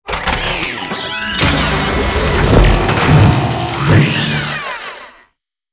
scoopretract.wav